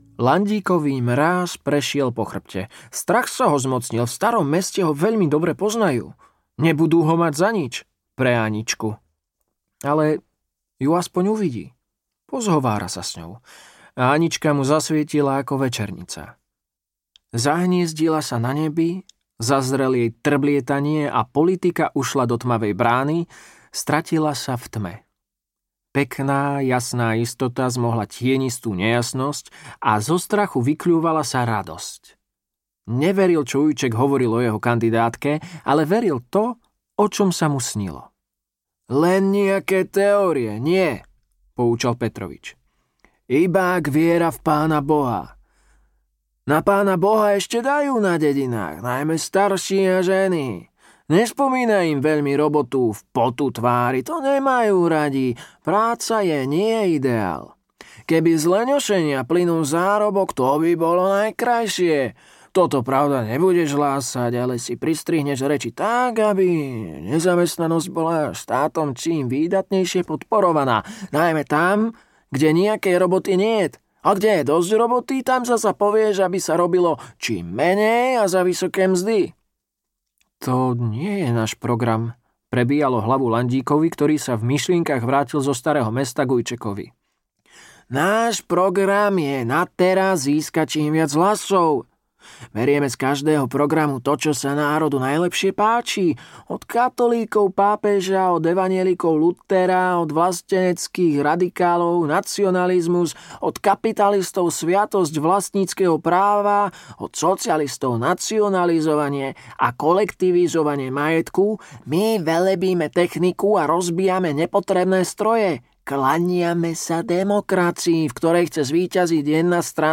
Demokrati audiokniha
Ukázka z knihy